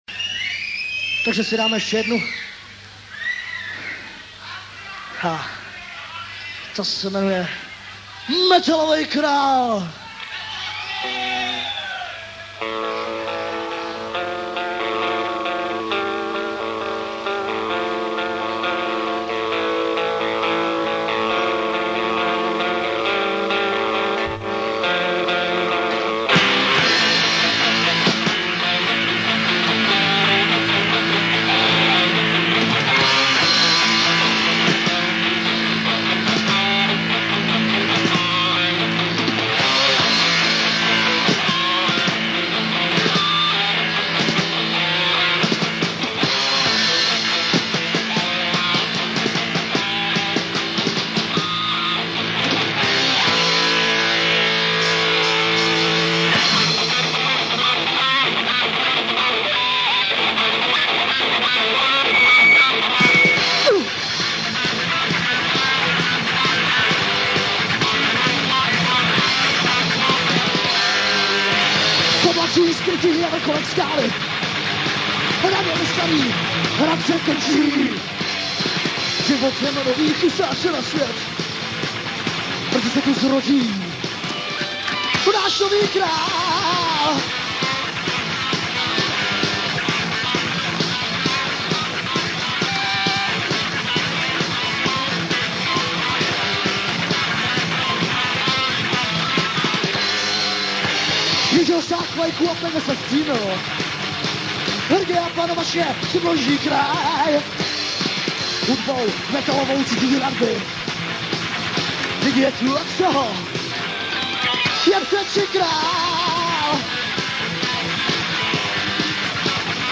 To u� ve tvorb� inklinoval k thrash metalu.